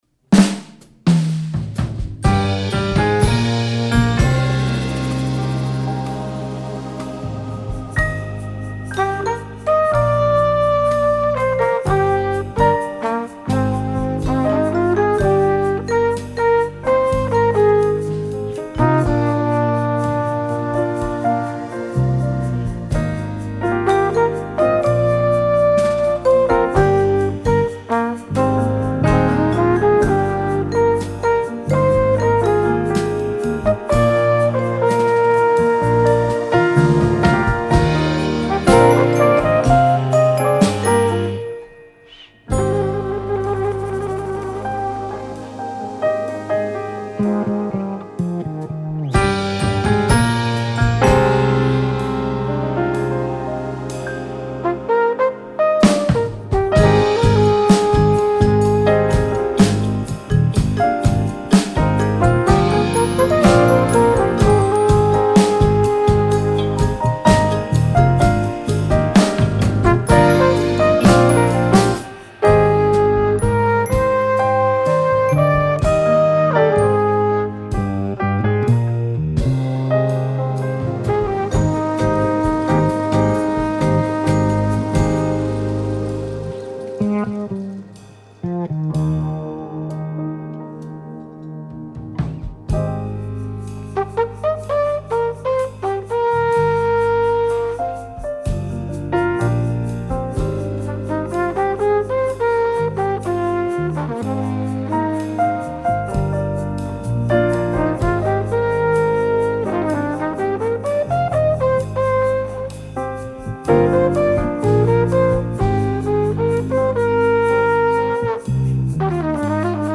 Drums, Percussion, Glockenspiel
Flugelhorn